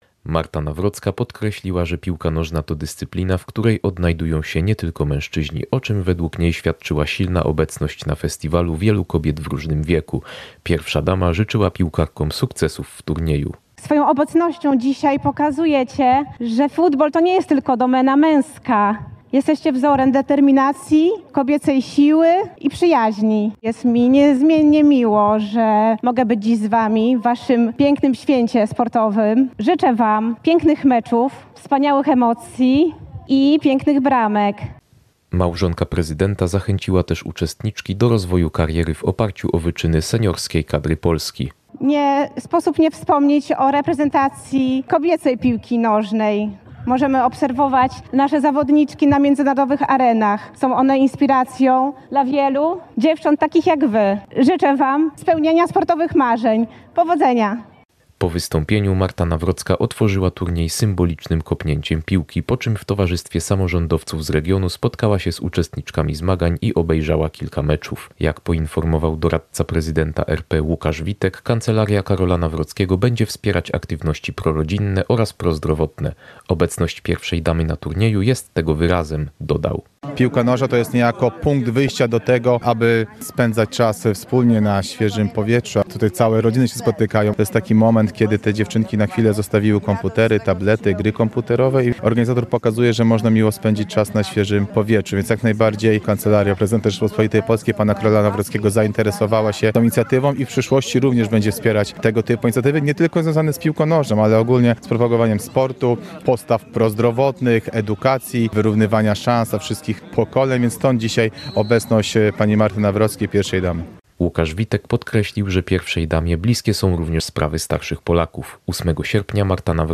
– powiedziała Pierwsza Dama RP Marta Nawrocka podczas IV Podkarpackiego Festiwalu Piłki Nożnej Kobiecej w Tarnobrzegu.
Relacja